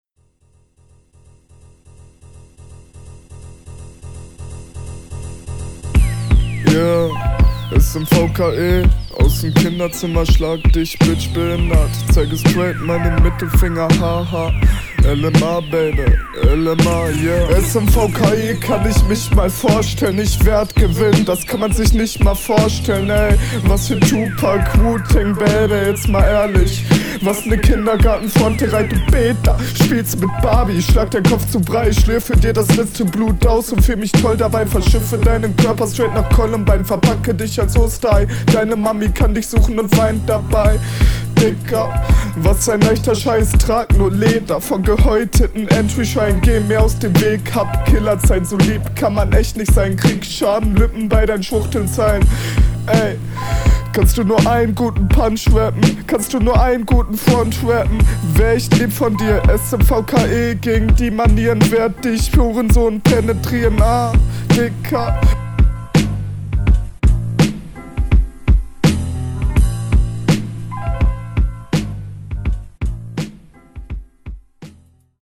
Hier finde ich deinen Flow/Betonung + Stimmeinsatz etwas daneben.